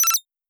HandScannerOk.wav